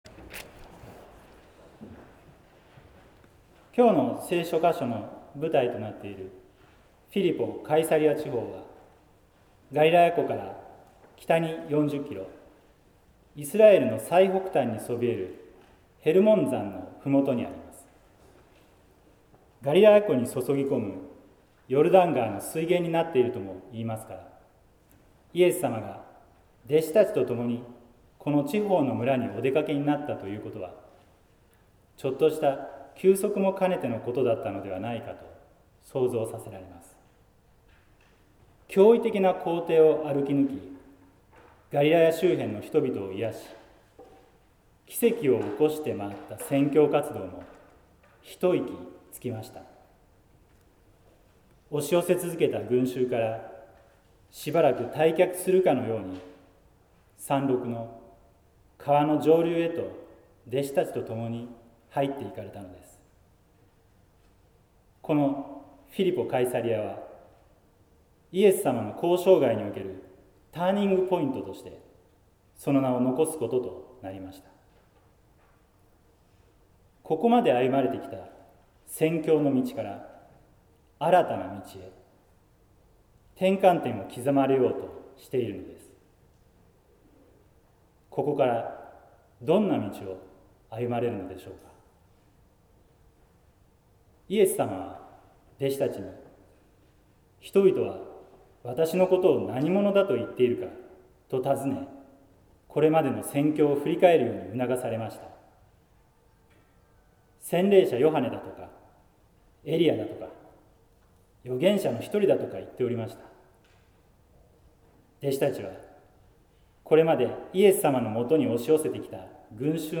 説教「神様が喜んでいる」（音声版） | 日本福音ルーテル市ヶ谷教会
説教「神様が喜んでいる」（音声版）